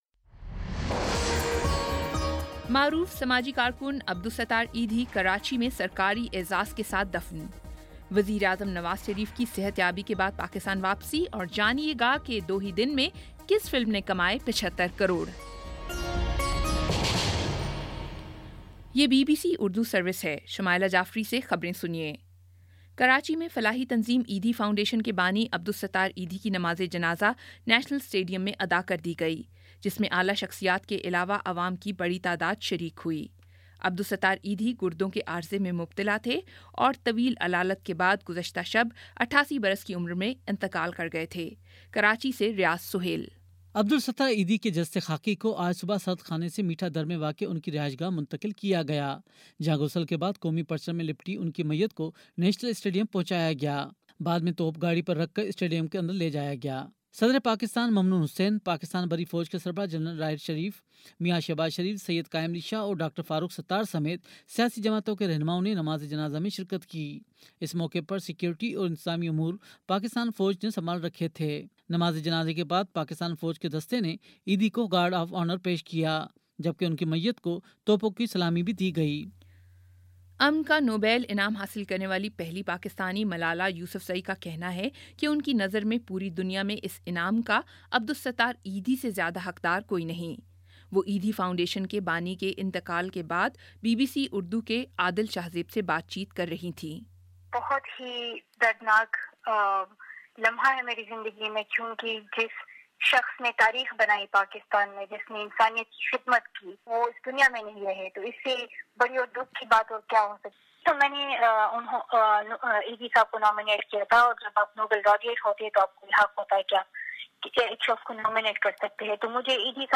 جولائی 09 : شام پانچ بجے کا نیوز بُلیٹن